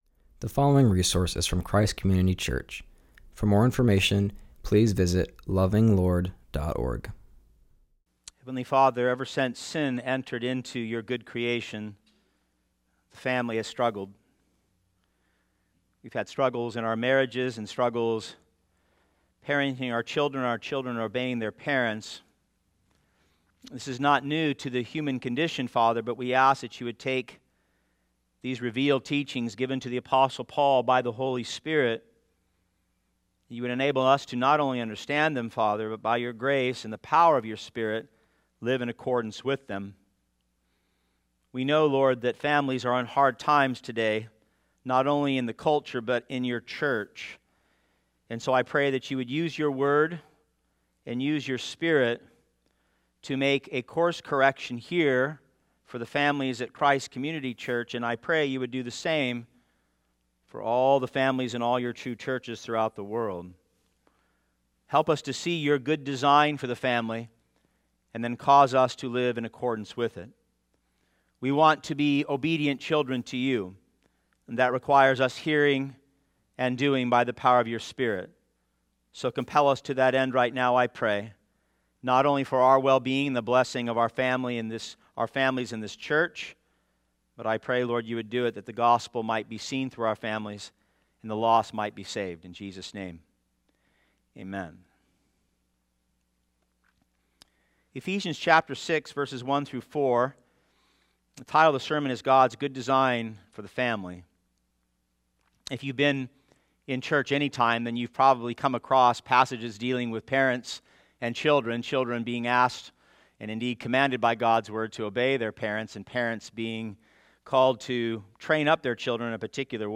preaches from Ephesians 6:1-4.